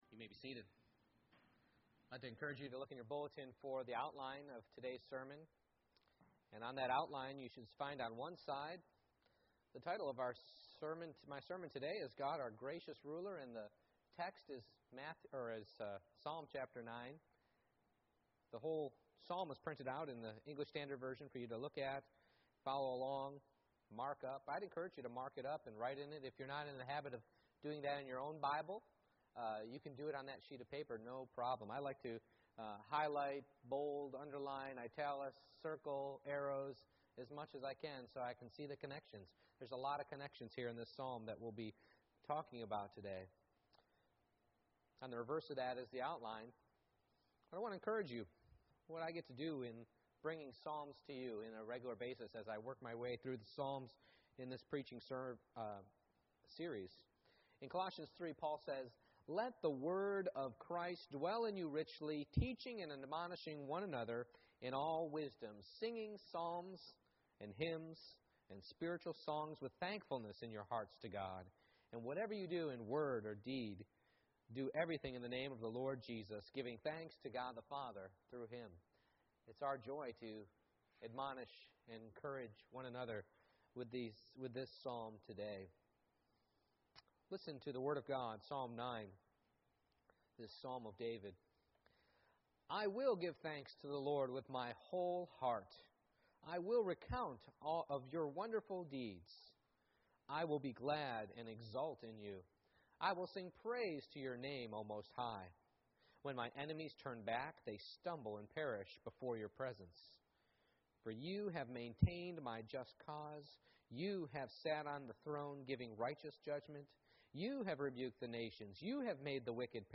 Psalm 9:1-20 Service Type: Morning Worship I. Praise is a Choice